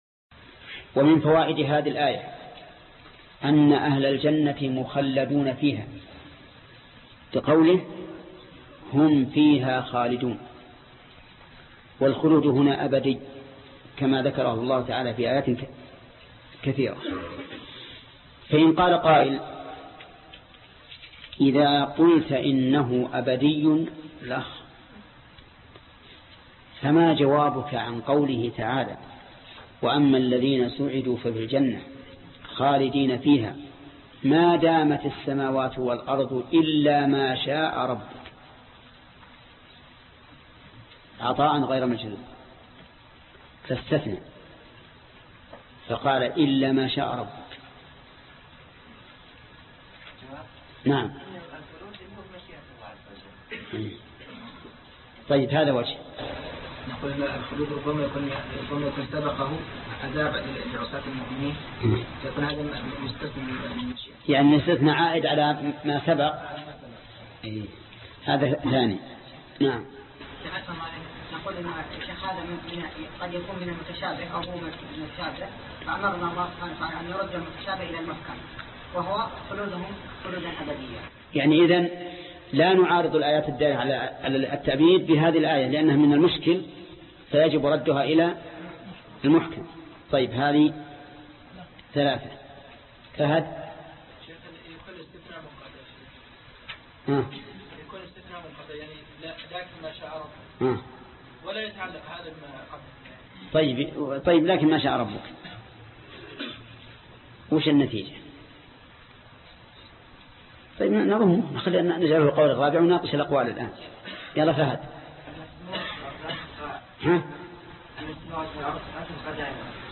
الدرس 184 فوائد الآيات 107و108 (تفسير سورة آل عمران) - فضيلة الشيخ محمد بن صالح العثيمين رحمه الله